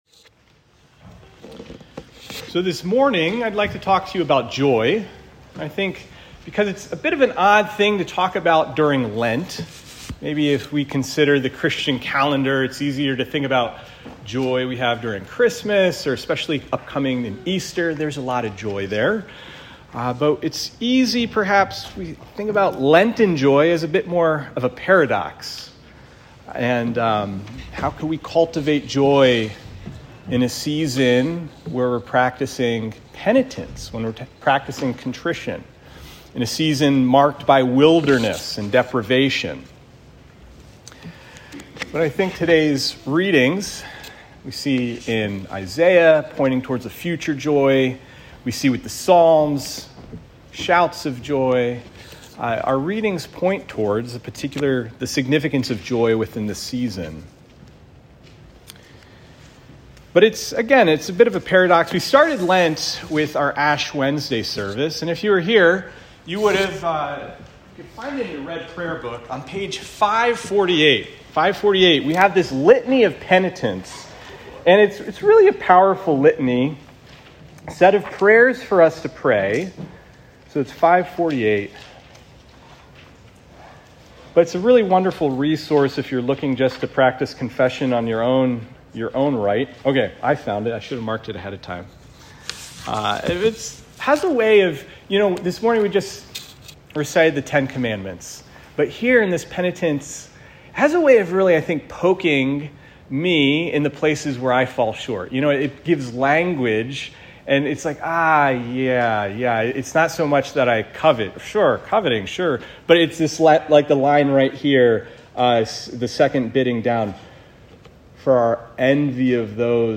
Sermons | Anglican Church of the Ascension